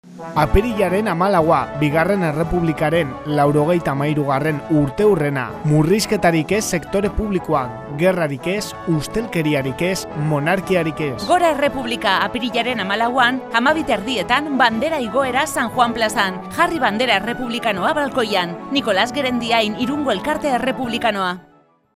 Iragarki laburrak "Radio Irun" irratian, apirilaren 9tik 14ra